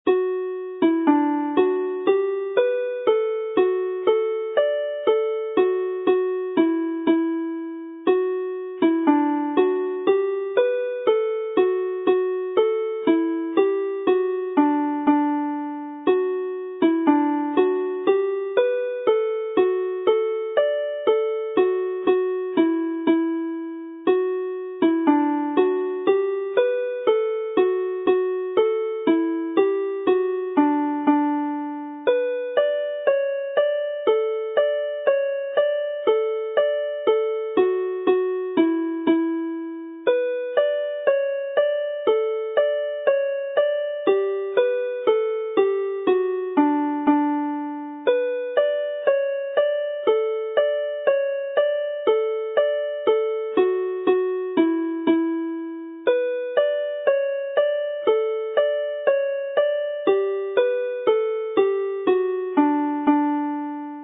mp3 + cordiau